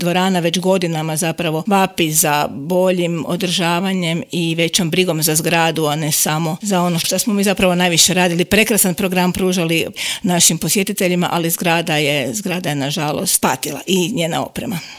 U intervjuu Media servisa rekla je kako je veoma izazovno raditi na toj poziciji.